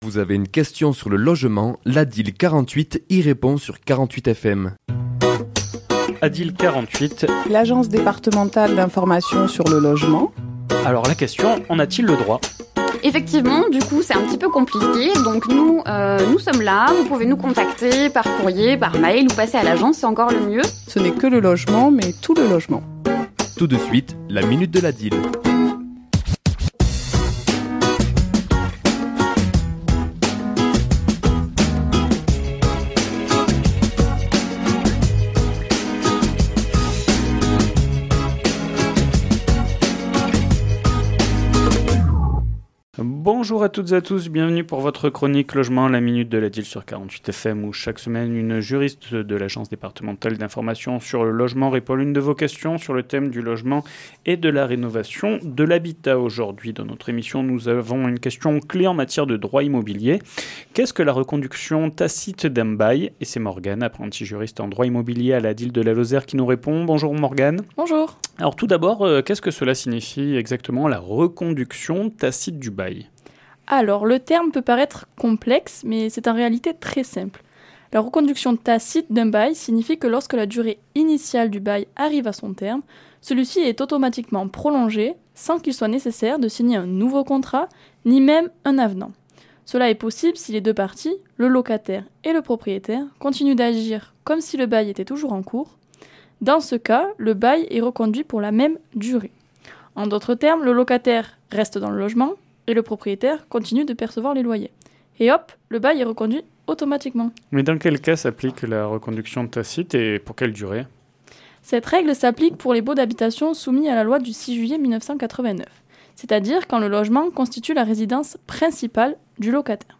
Chronique diffusée le mardi 18 février à 11h et 17h10